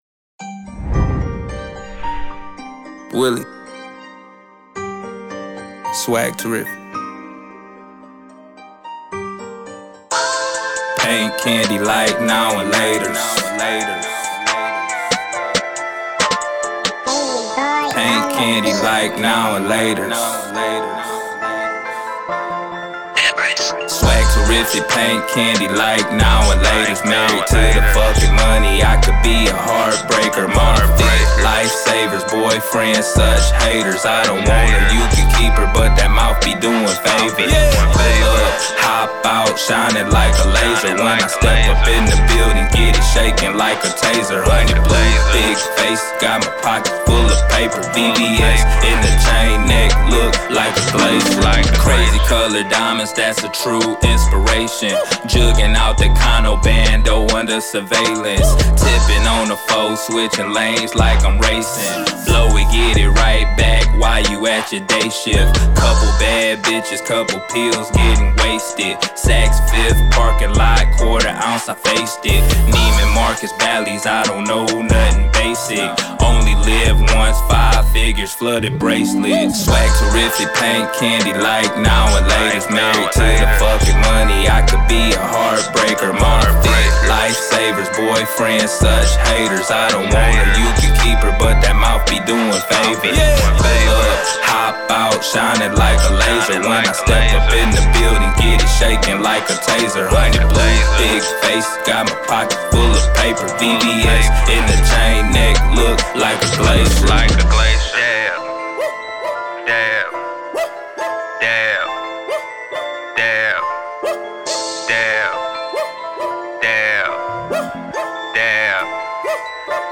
Hiphop
Description : New Hip Hop